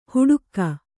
♪ huḍukka